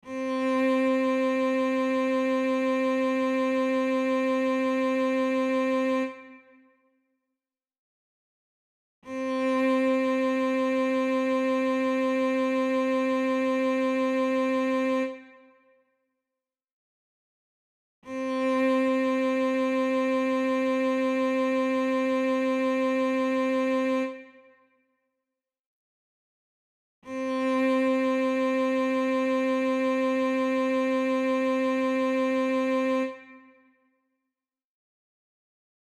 On the piano, what note is this?